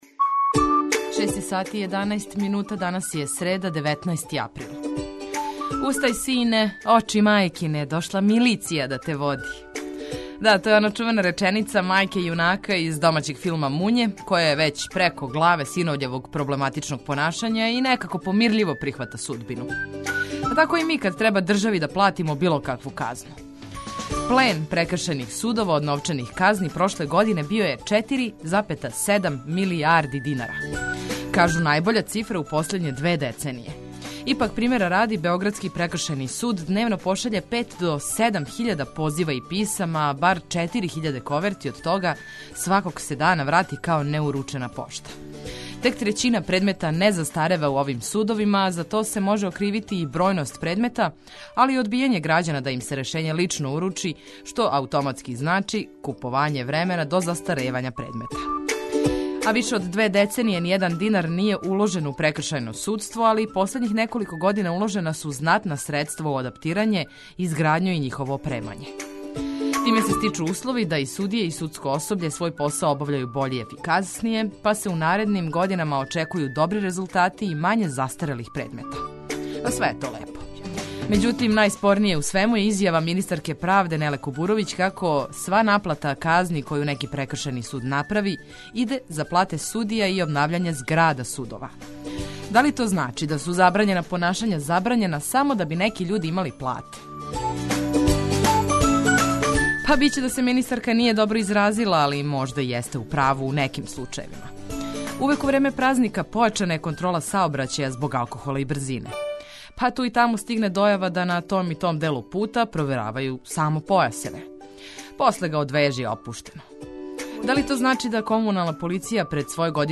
Прве сате јутра испунили смо музиком за разбуђивање и информацијама које су важне.